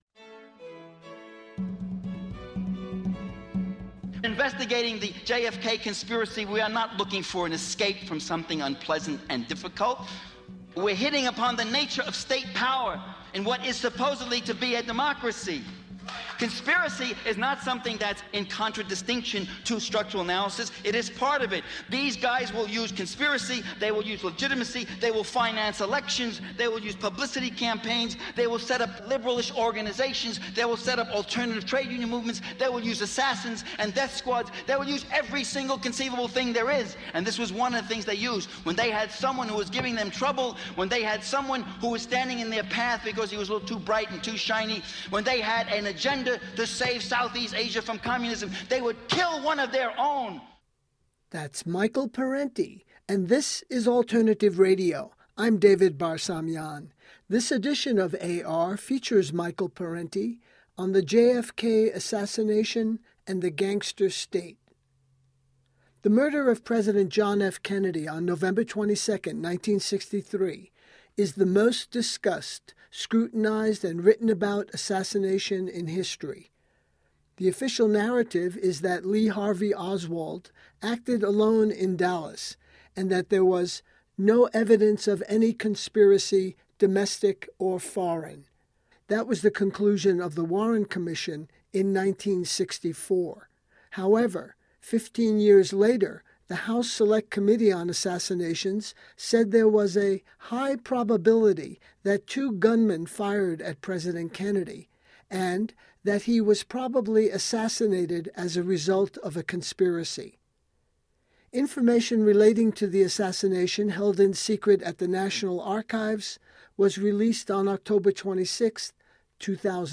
File Information Listen (h:mm:ss) 0:57:00 Michael Parenti The JFK Assassination & the Gangster State Download (2) Parenti-JFKGangsterState.mp3 34,309k 80kbps Mono Comments: Berkeley, CA Listen All